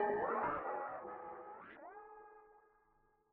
G# Tupac Bell x.wav